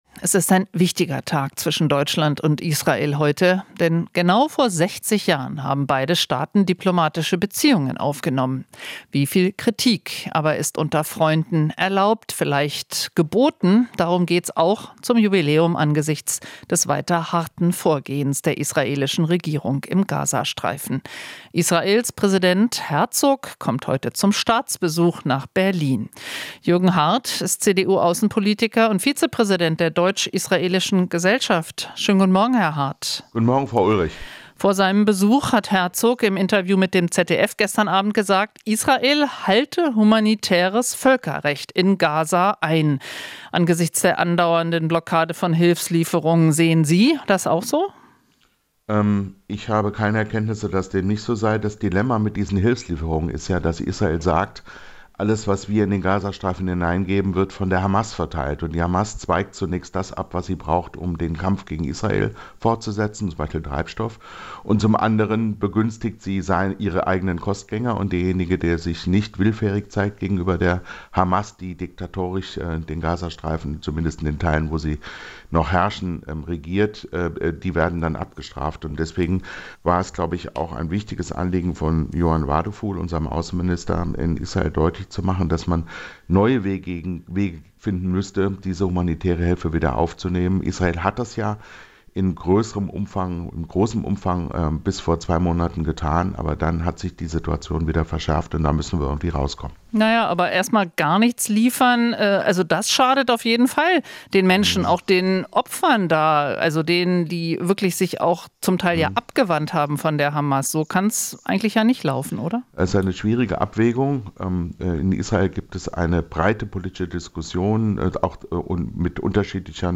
Interview - Hardt (CDU): "Brauchen neue Wege für Gaza-Hilfslieferungen"